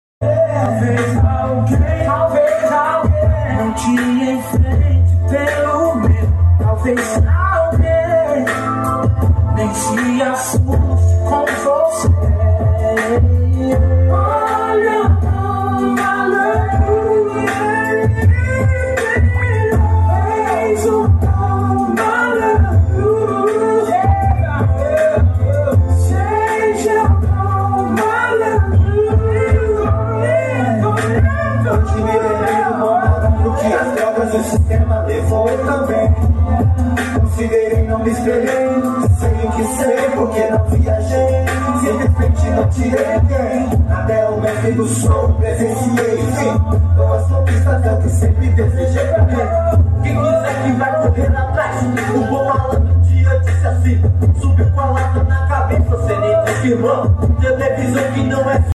uma verdadeira pancada de grave
ligado no volume 10 o bicho já mostra potencia